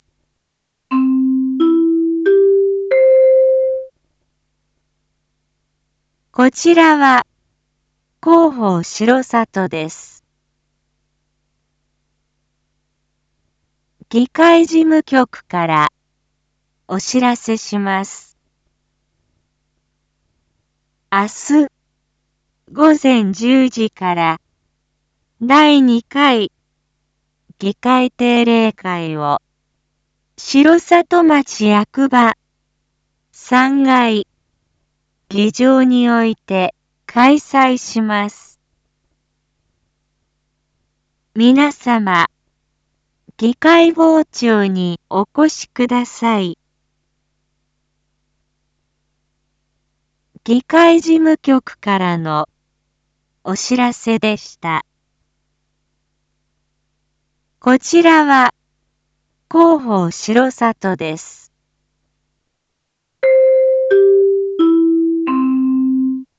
一般放送情報
Back Home 一般放送情報 音声放送 再生 一般放送情報 登録日時：2024-06-03 19:01:08 タイトル：第２回議会定例会① インフォメーション：こちらは広報しろさとです。